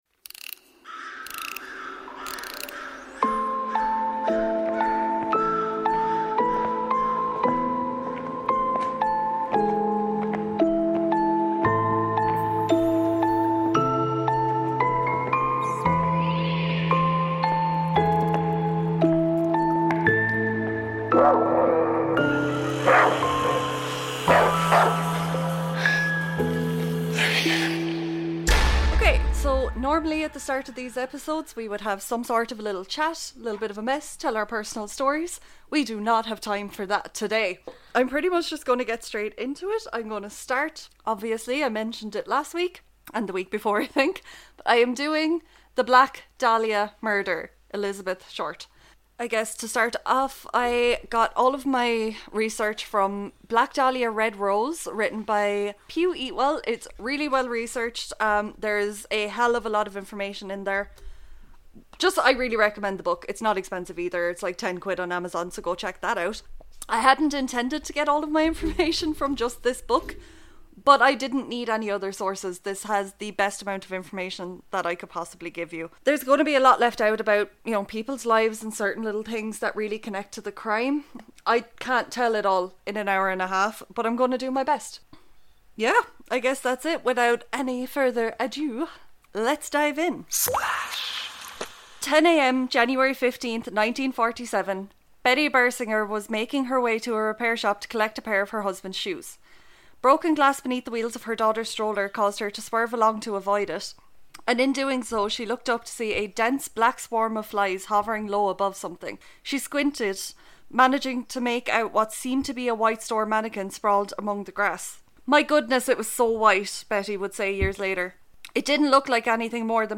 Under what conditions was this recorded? For your own sanity & safety, don't listen at full volume while wearing headphones or operating heavy machinery.